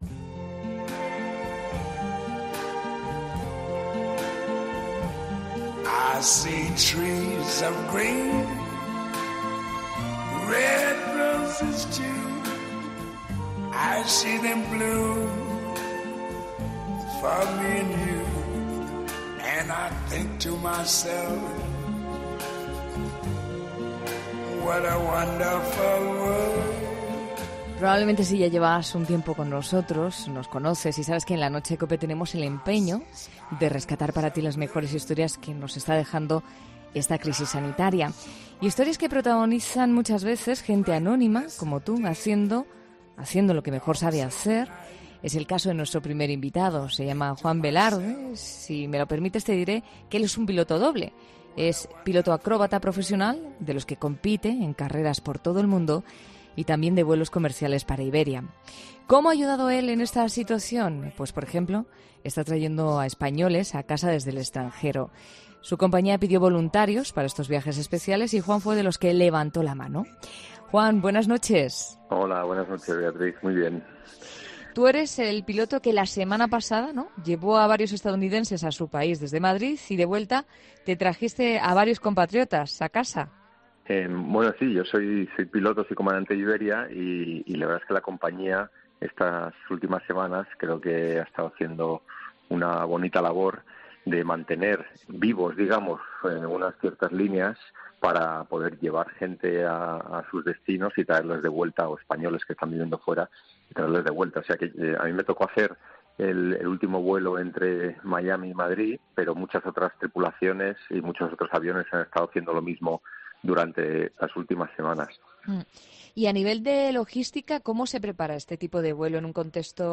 En 'La Noche de COPE hablamos con cuatro personas que han querido aportar su granito de arena de forma diferente